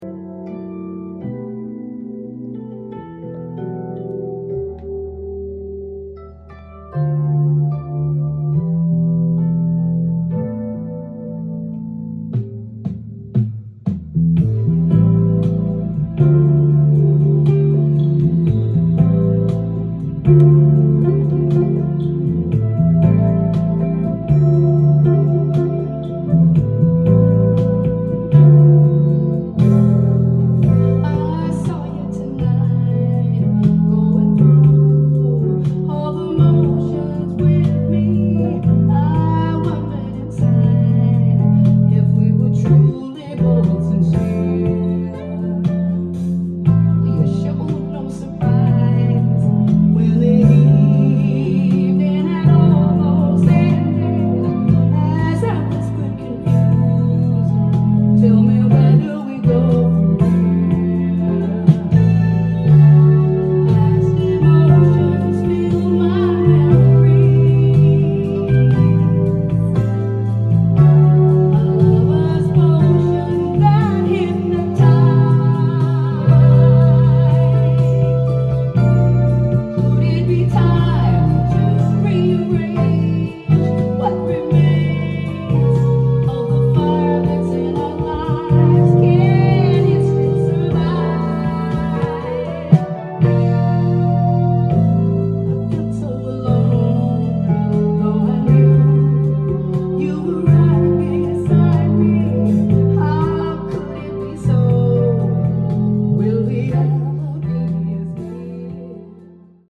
ジャンル：FUSION
店頭で録音した音源の為、多少の外部音や音質の悪さはございますが、サンプルとしてご視聴ください。